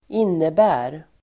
Uttal: [²'in:ebä:r]